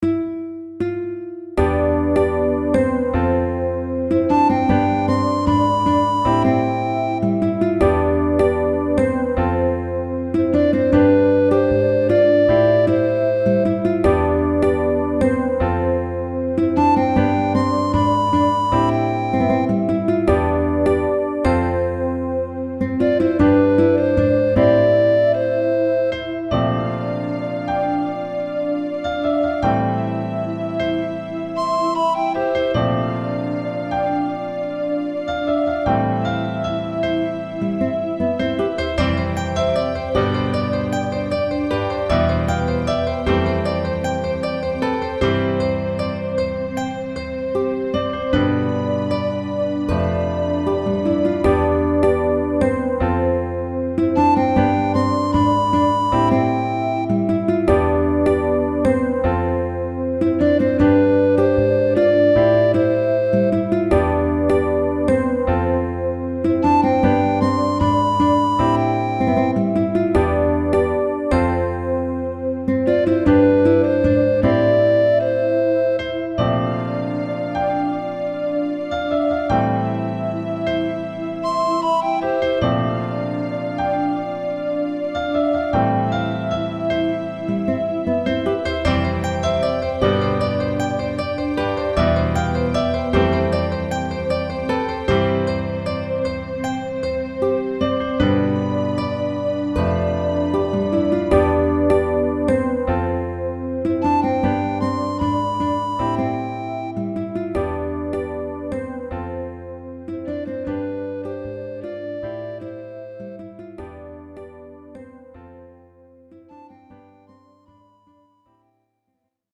「逆転姉妹のテーマ」はポップな仕上がりですが、こちらは生ギターの癒し系の曲です。
SD系のピアノはイイ感じに鳴ってくれるので気に入ってます。